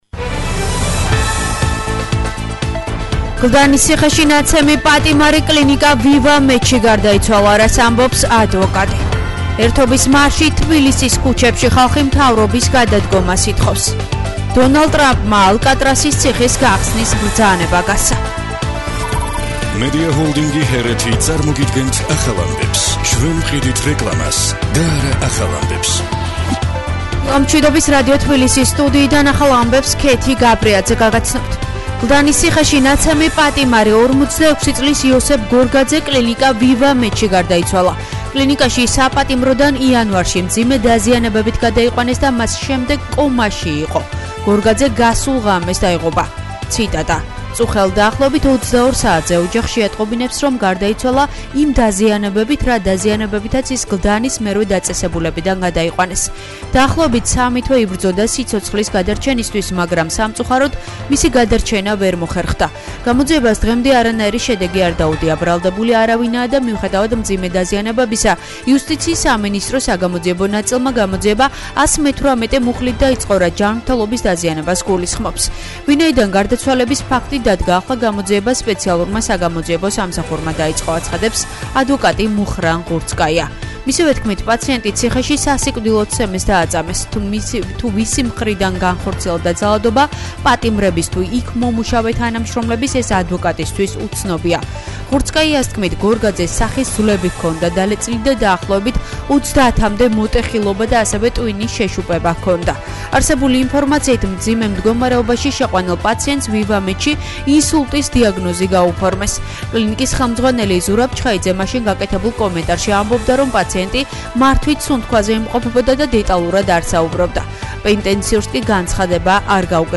ახალი ამბები 10:00 საათზე